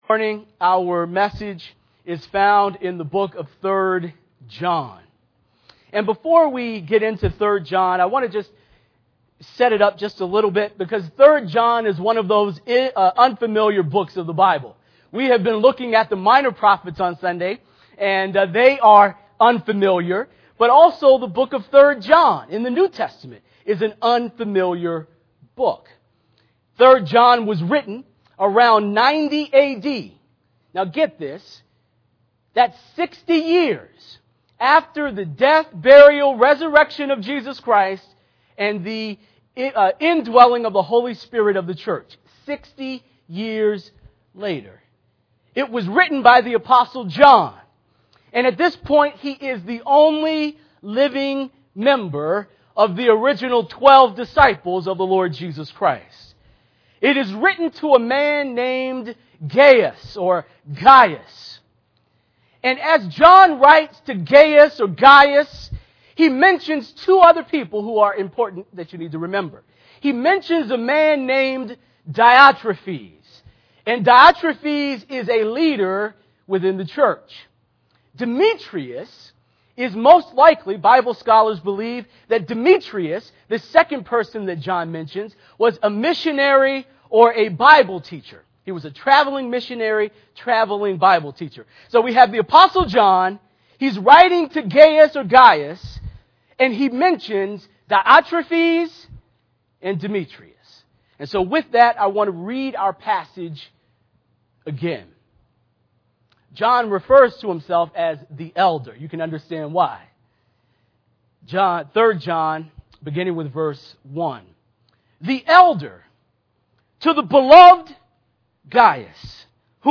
Crossroads Church of Hillside Sermons